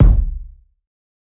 KICK_DEATH.wav